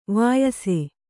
♪ vāyase